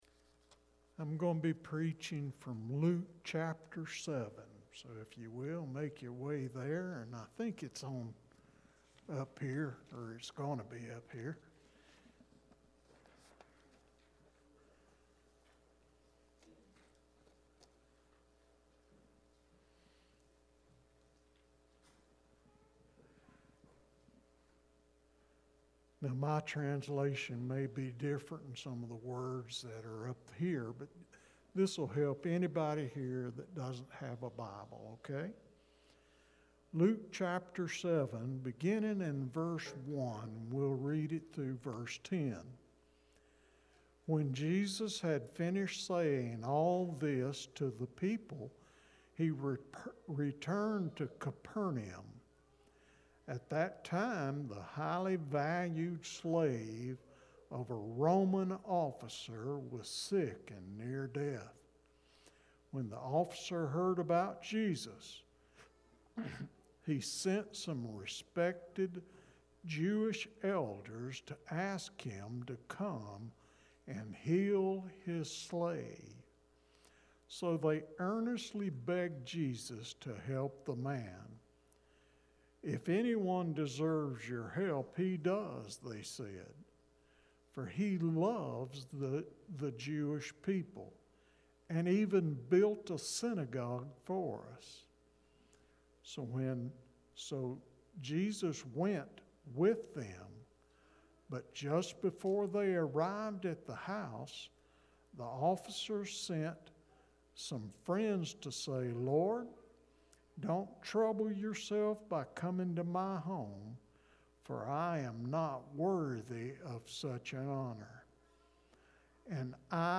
sermon-audio-aug-9-2020-final-edited.mp3